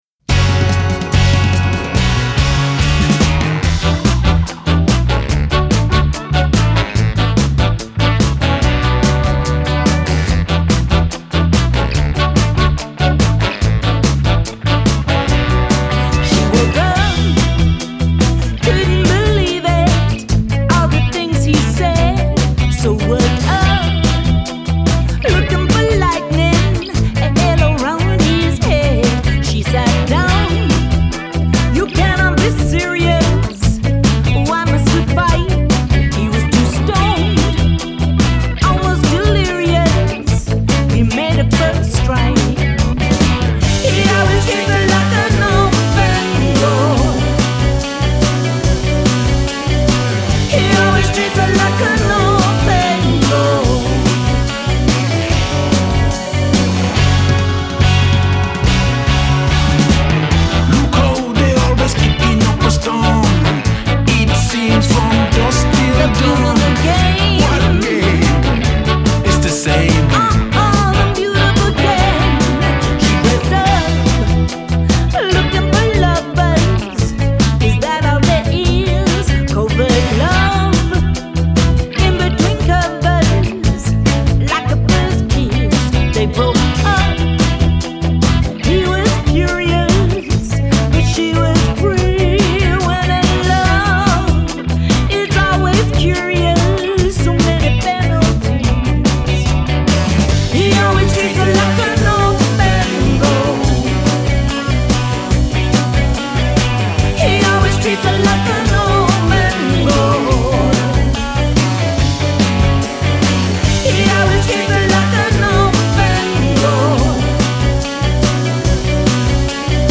Genre: Ska, Reggae, Dub